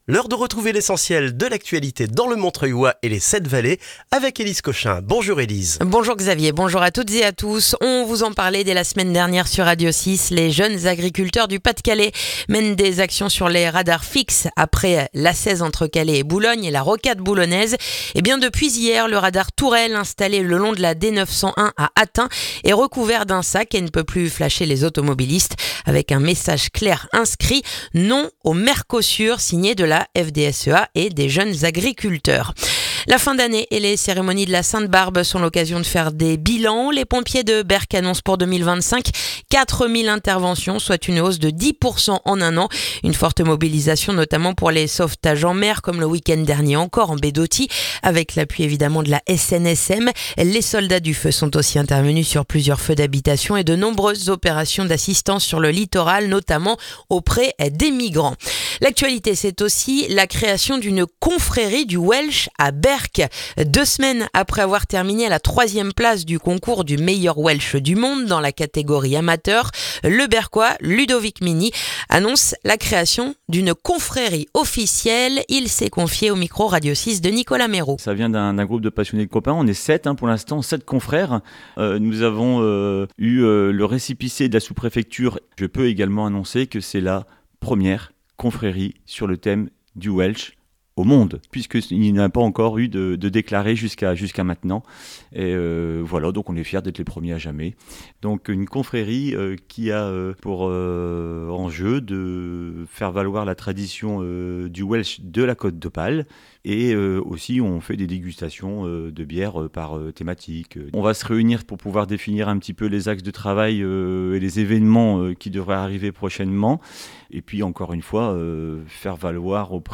Le journal du mercredi 17 décembre dans le montreuillois